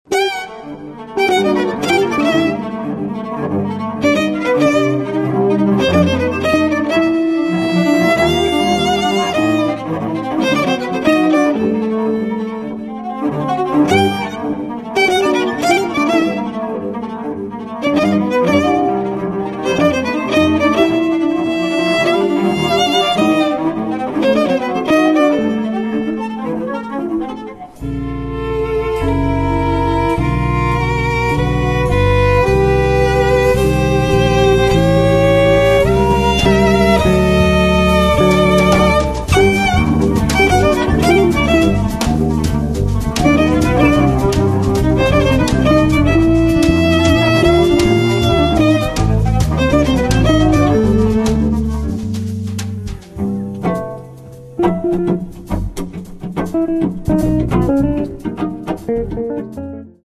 violin
accordion
cello
electric guitar
electric bass
drums